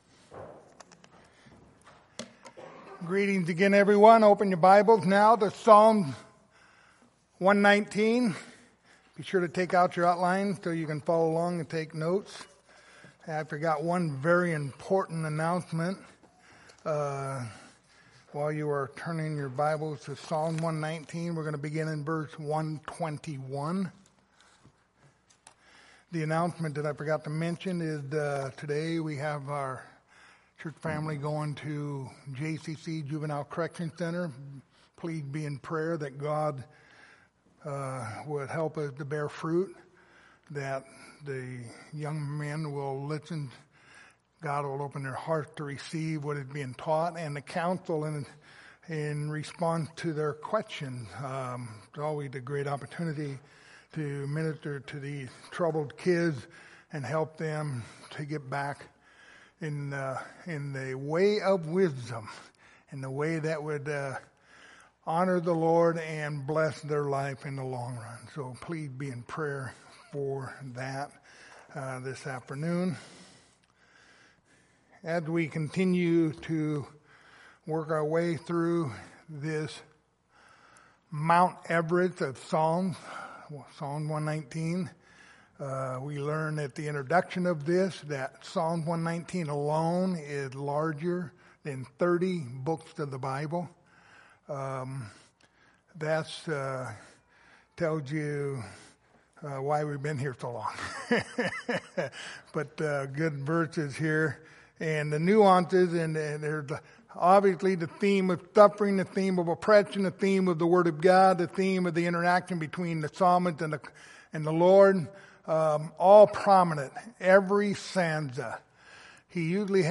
Passage: Psalm 119:121-128 Service Type: Sunday Morning